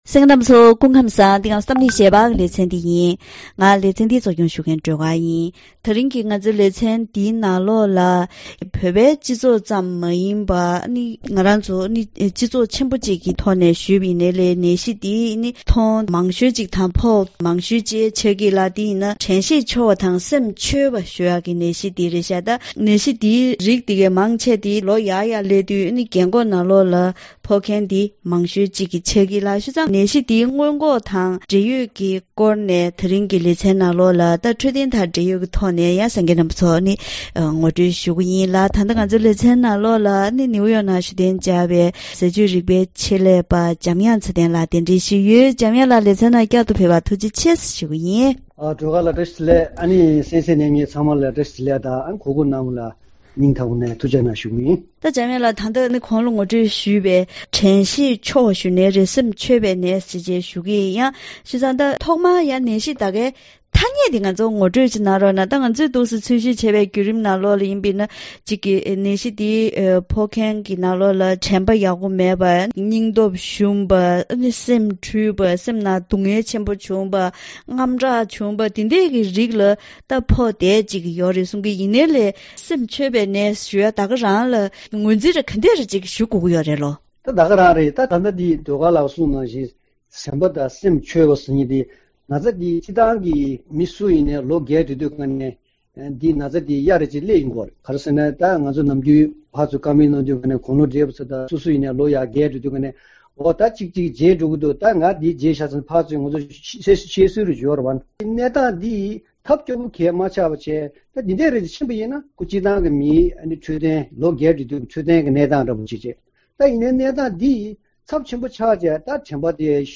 ད་རིང་གི་གཏམ་གླེང་ཞལ་པར་ལེ་ཚན་ནང་དྲན་ཤེས་ཉམས་པའི་ནད་གཞི་འདི་དམིགས་བསལ་ཤར་ཕྱོགས་མི་རིགས་ནང་ཁྱབ་གདལ་ཆེ་རུང་འགྲོ་བཞིན་ཡོད་པས། ནད་གཞི་འདིའི་བྱུང་རྐྱེན་དང་། ནད་རྟགས། སྔོན་འགོག་ཆེད་རྒྱུན་དུ་ཟས་བཅུད་ལ་དོ་སྣང་ཇི་ལྟར་དགོས་མིན་སོགས་ཀྱི་སྐོར་ངོ་སྤྲོད་ཞིབ་ཕྲ་ཞུས་པ་ཞིག་གསན་རོགས་གནང་།